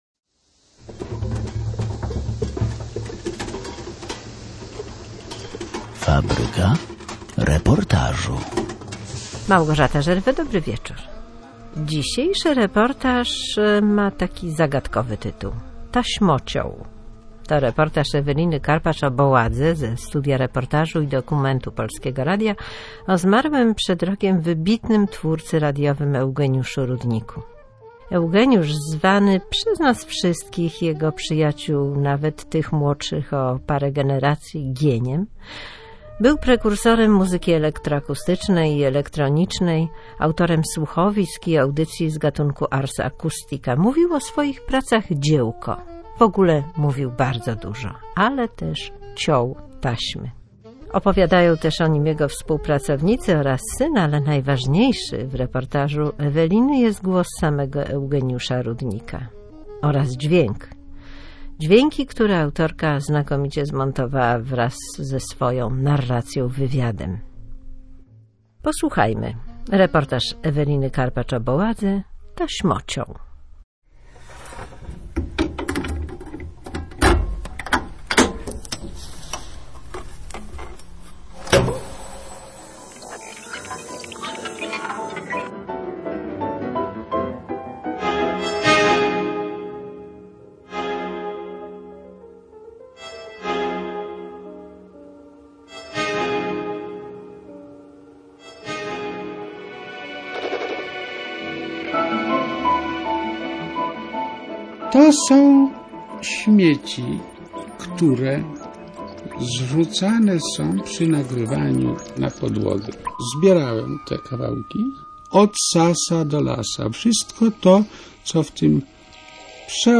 Pojawia się także głos samego Eugeniusza Rudnika oraz dźwięki, które autorka znakomicie zmontowała wraz z narracją – wywiadem.
Reportaż jest przykładem ciekawego opowiadania in memoriam.